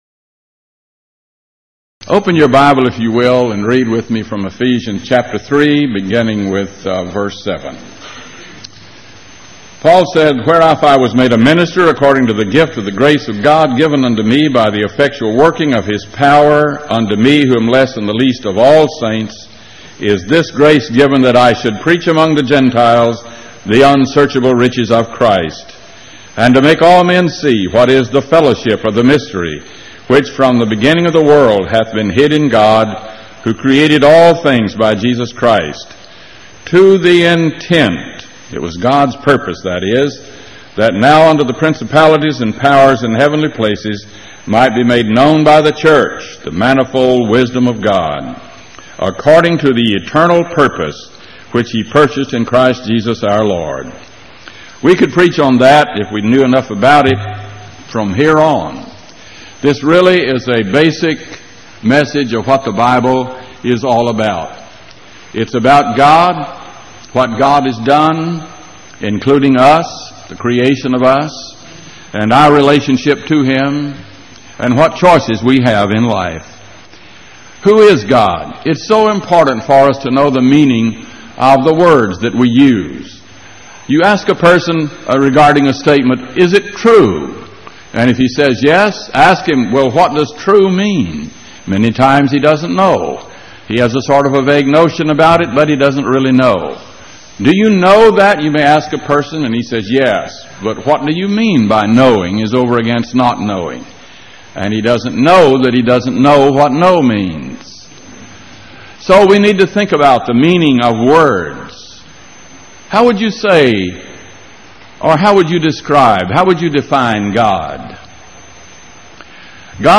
Event: 1989 Power Lectures
lecture